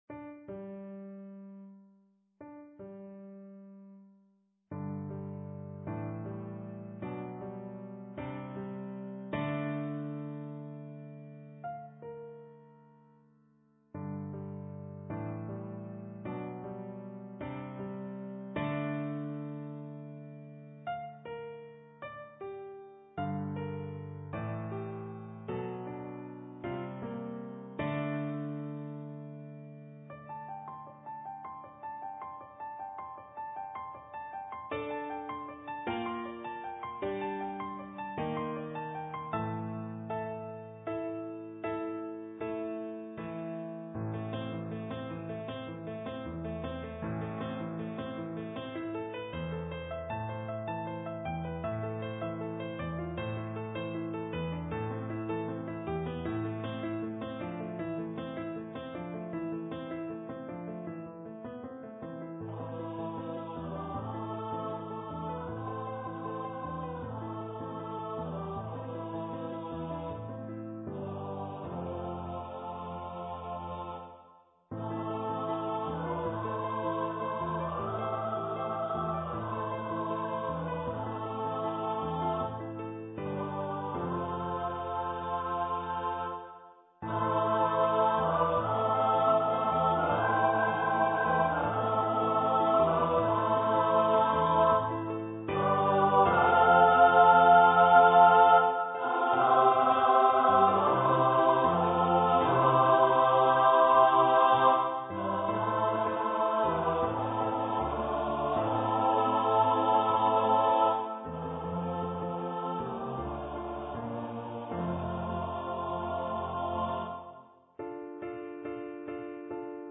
A chamber opera
Chorus (with solos).